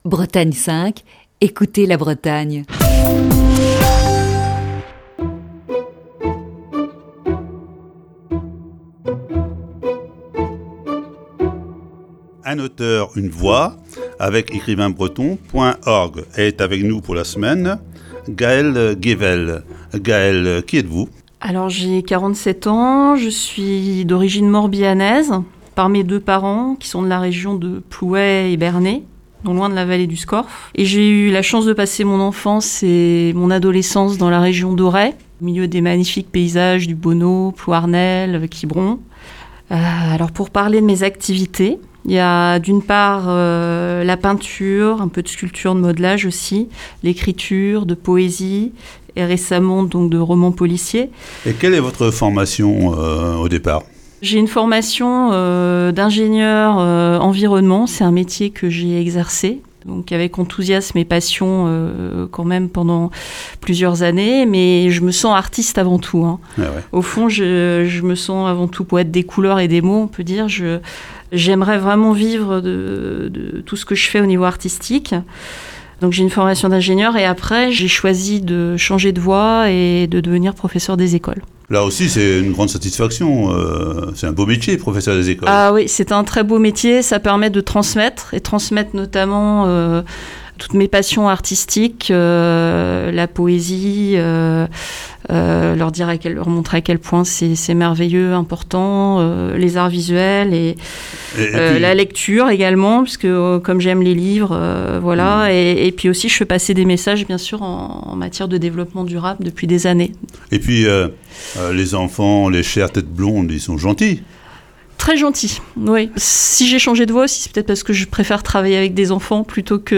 une série d'entretiens